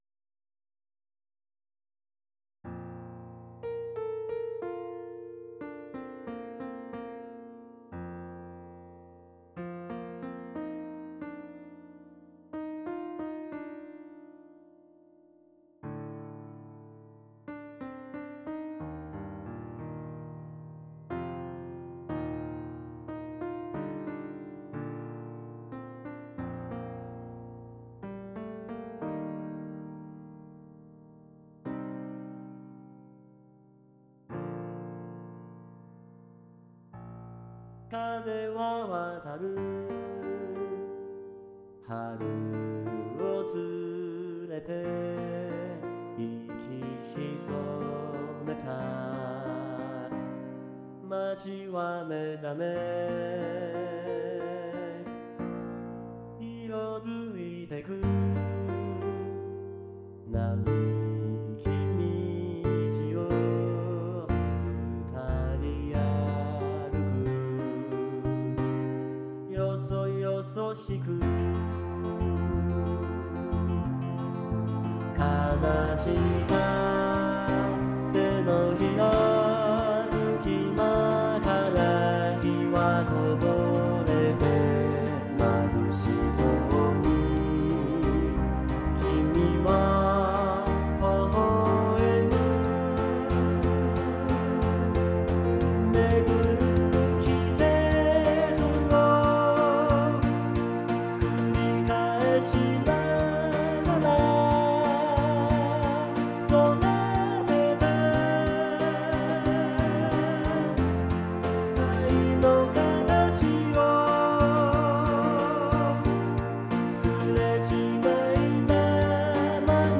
バラード